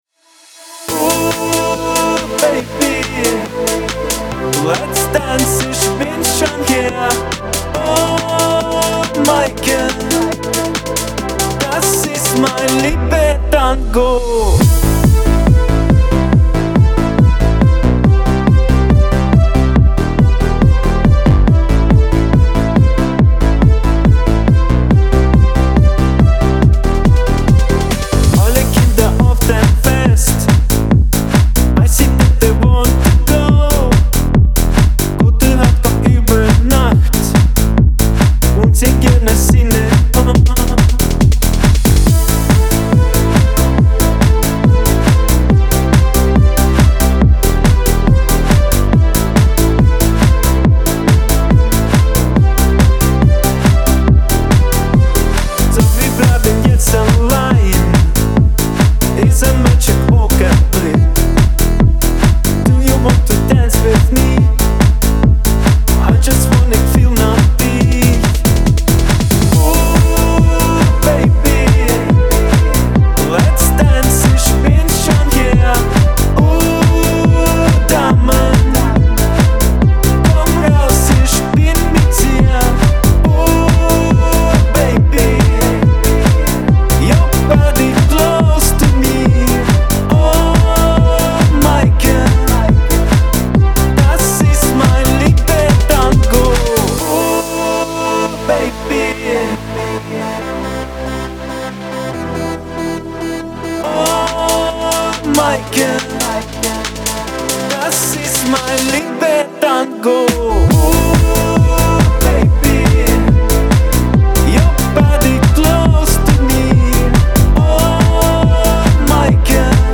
pop , эстрада